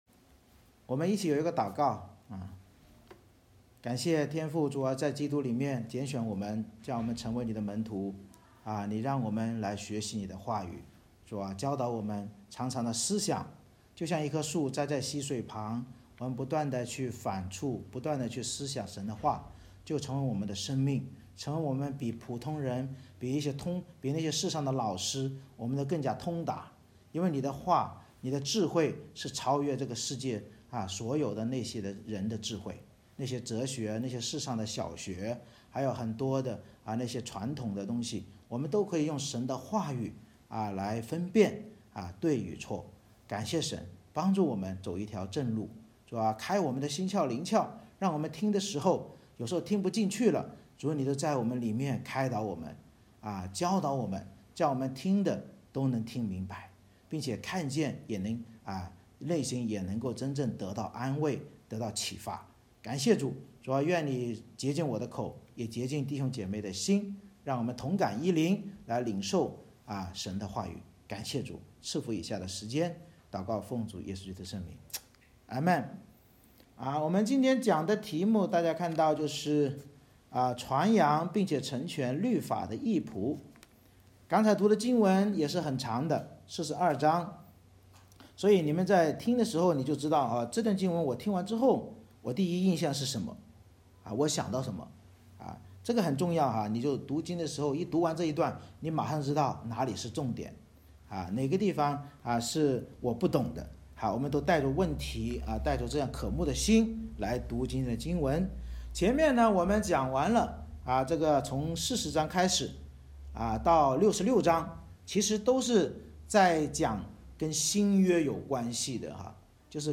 以赛亚书Isaiah42:1-25 Service Type: 主日崇拜 神藉着先知宣告祂必做一件新事，就是拣选义仆降临地上传扬并成全律法，而仆人以色列却因眼瞎耳聋遭灾祸，教导我们要效法义仆基督耶稣的传道行道。